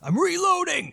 Voices / Male / Reloading.wav
Reloading.wav